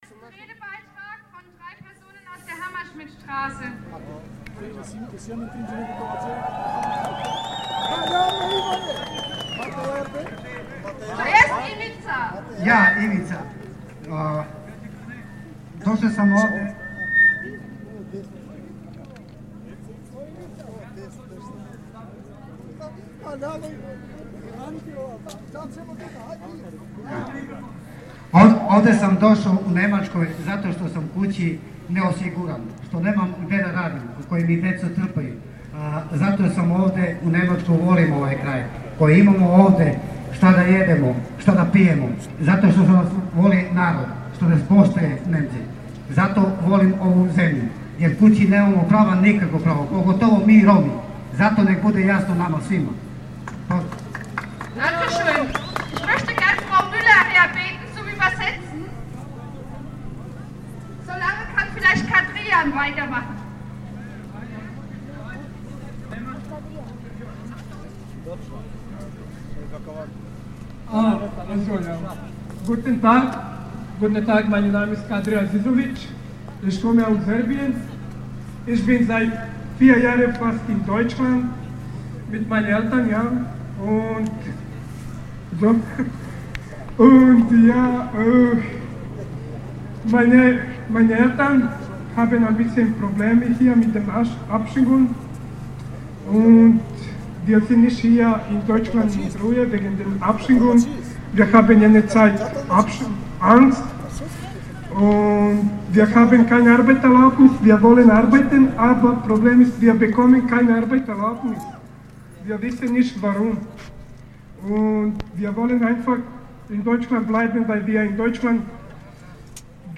Rund 1200 Menschen demonstrierten am heutigen Samstag Nachmittag, trotz ungemütlicher nasser Kälte, für einen sofortigen Abschiebestopp.
Die Demospitze bildeten zahlreiche Roma aus den verschiedenen Flüchtlingswohnheimen (Rede von Menschen aus dem Wohnheim in der Hammerschmiedstraße
"Wir wollen bleiben" riefen sie.